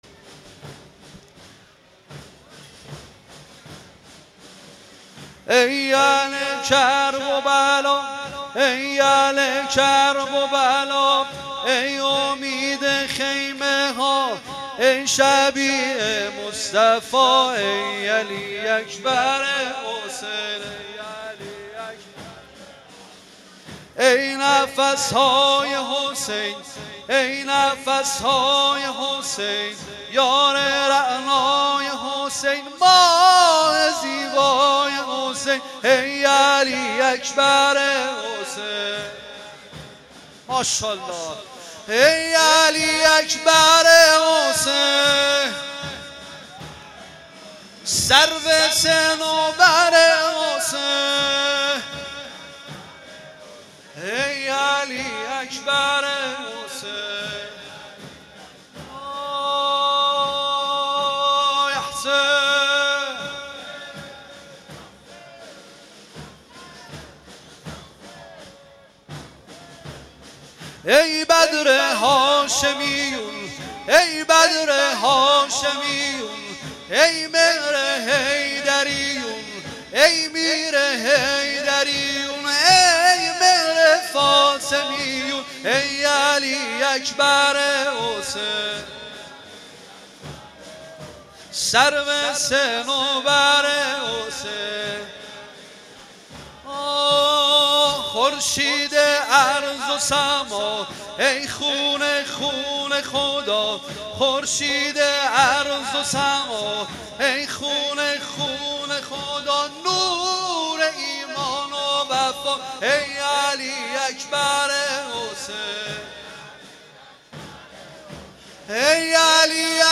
محرم و صفر 96
شور محرم 96